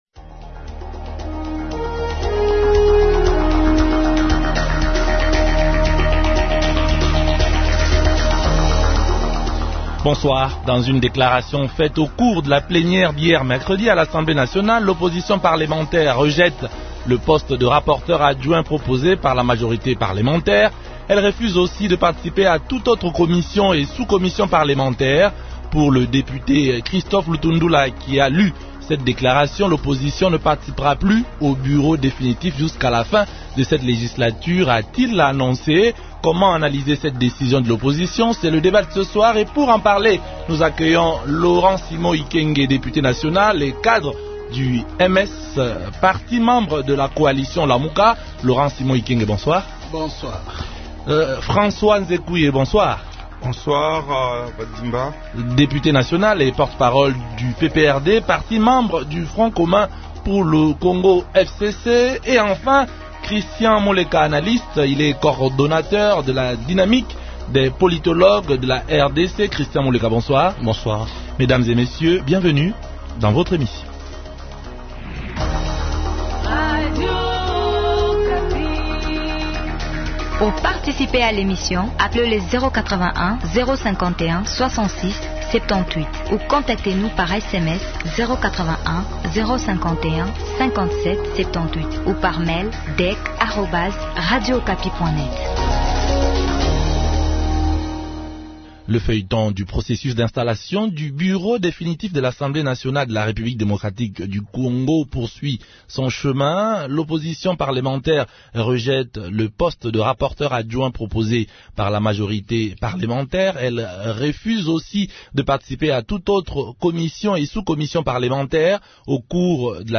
Comment analyser cette décision de l’opposition ? Invités Laurent-Simon Ikenge, Député national et cadre du MS, parti membre de la coalition Lamuka. François Nzekuye, Député national et Porte-parole du PPRD, parti membre du Front commun pour le Congo (FCC).